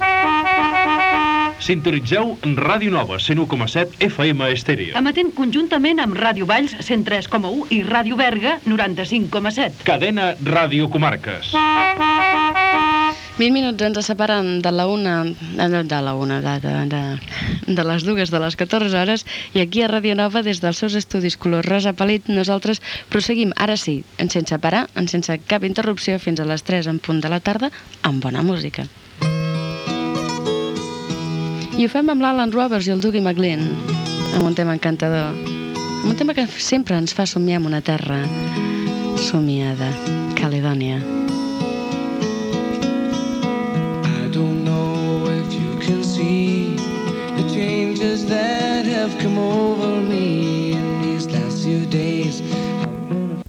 951c1b9fbd79af3c0c5845bc7fec73e524fa32b6.mp3 Títol Ràdio Nova Barcelona Emissora Ràdio Nova Barcelona Cadena Ràdio Comarques Catalanes Titularitat Privada estatal Descripció Indicatiu, hora i tema musical.